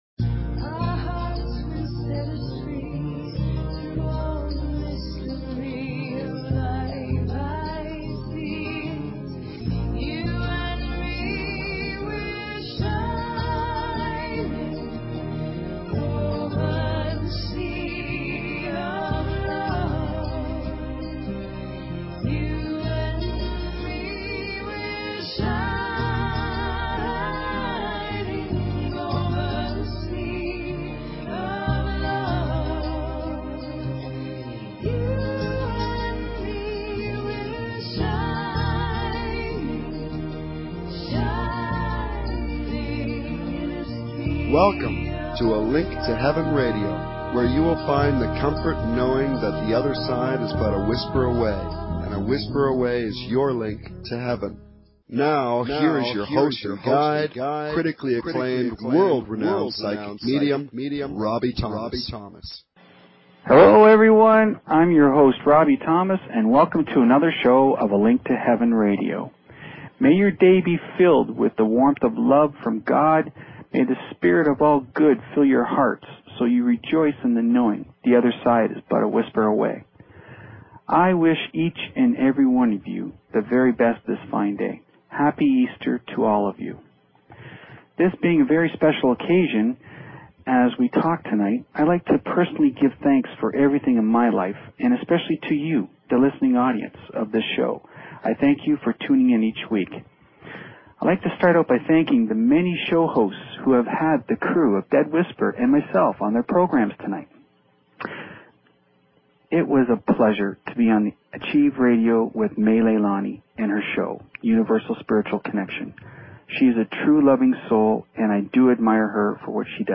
Talk Show Episode, Audio Podcast, A_Link_To_Heaven and Courtesy of BBS Radio on , show guests , about , categorized as
Free Psychic Readings for call-ins